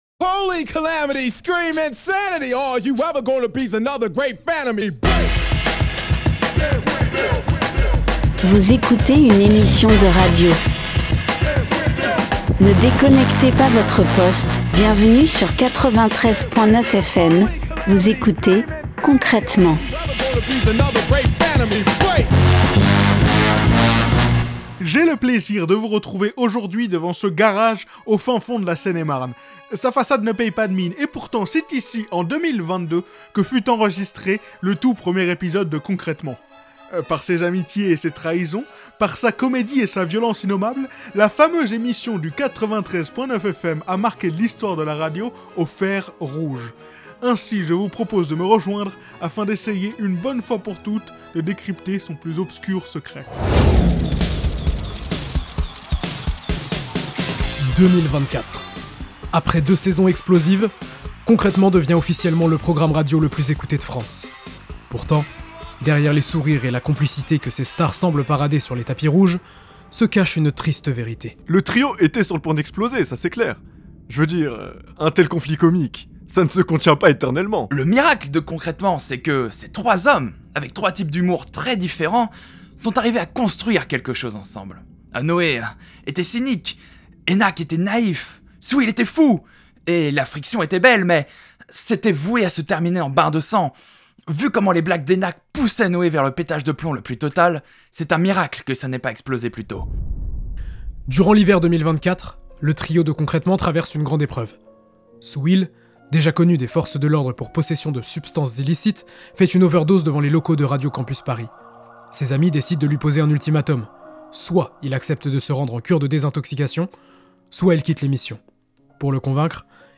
Partager Type Création sonore Société vendredi 7 mars 2025 Lire Pause Télécharger Les temps ont changé.